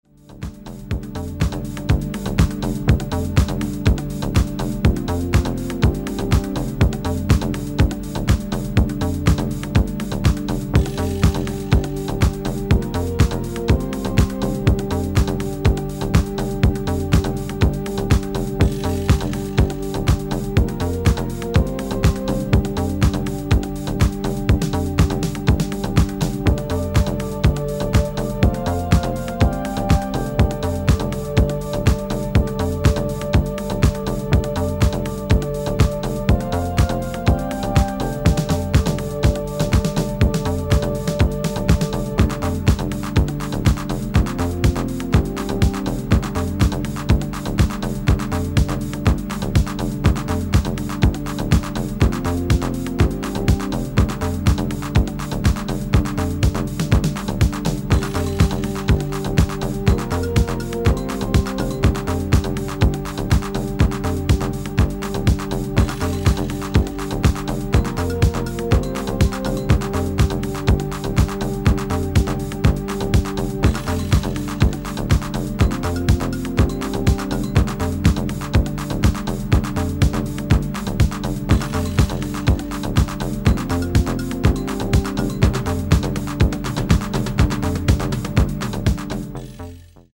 Soundexample: Roland Juno 106 bassline, Casio CZ-1 string, TR707 and 727 percussion, sequenced with Renoise